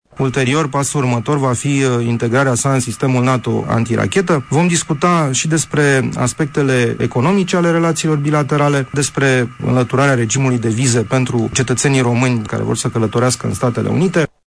Aurescu a declarant la RRA că vizita responsabilului American are loc şi în contextual în care în acest an urmează să devină operaţională baza de la Deveselu: